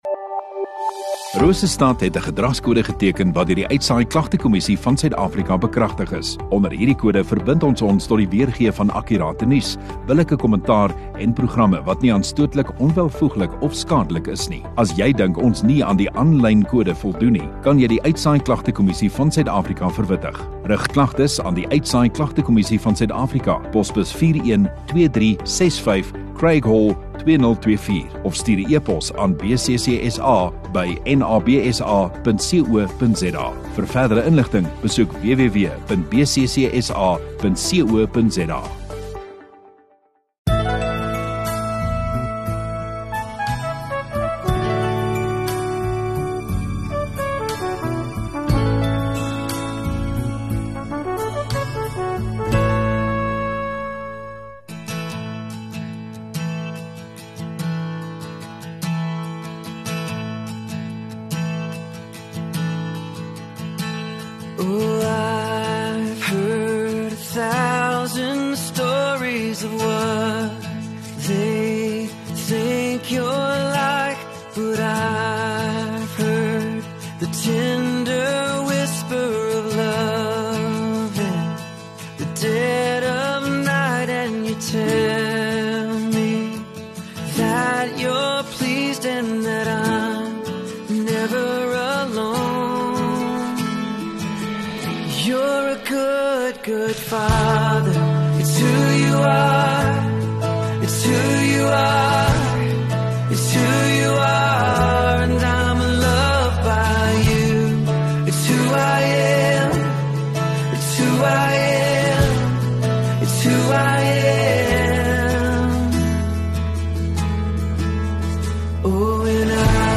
25 May Sondagaand Erediens